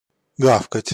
Ääntäminen
Synonyymit лаять брехать Ääntäminen Tuntematon aksentti: IPA: /ˈɡafkətʲ/ Haettu sana löytyi näillä lähdekielillä: venäjä Käännöksiä ei löytynyt valitulle kohdekielelle.